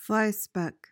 PRONUNCIATION: (FLY-spek) MEANING: noun:1.